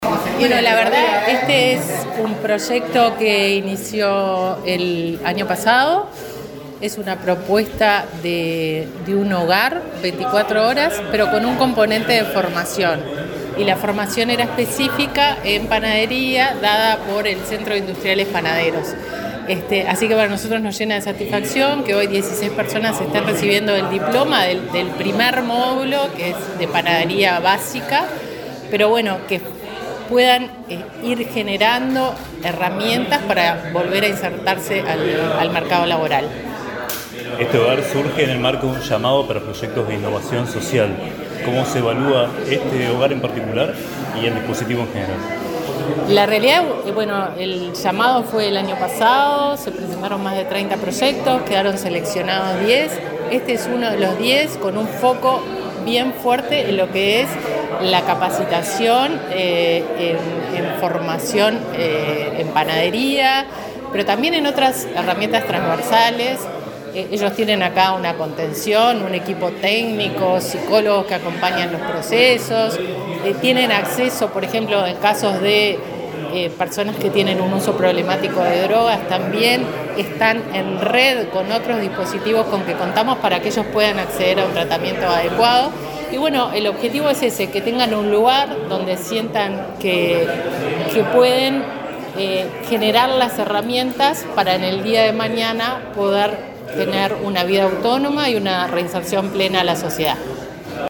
Entrevista a la directora nacional de Protección Social del MIDES, Fernanda Auersperg